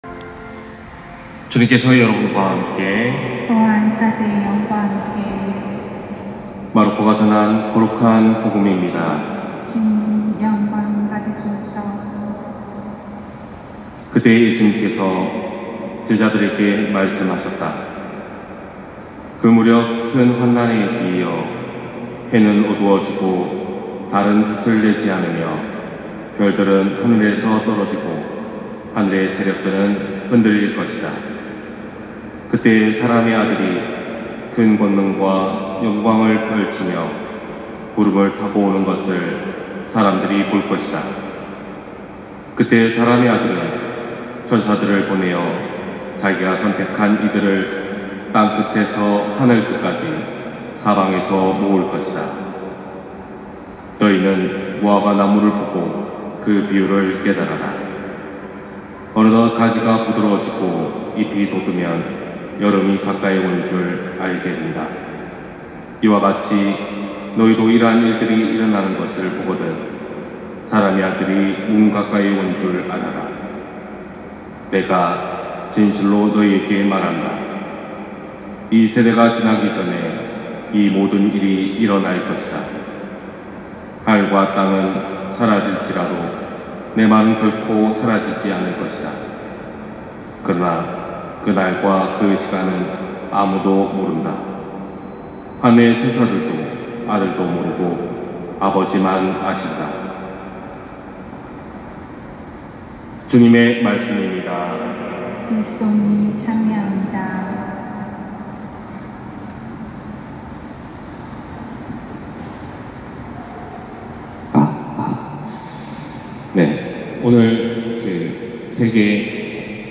241116신부님 강론말씀